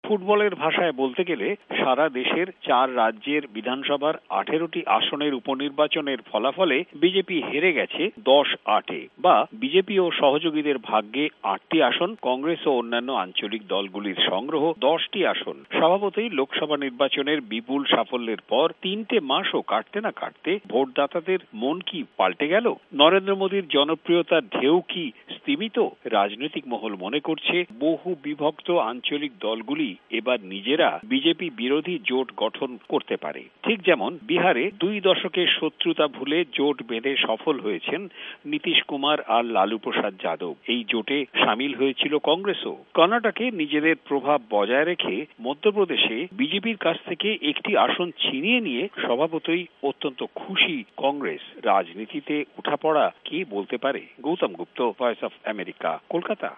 রাজনীতি ও পথ শিশুদের বিষয়ে কলকাতা সংবাদদাতাদের রিপোর্ট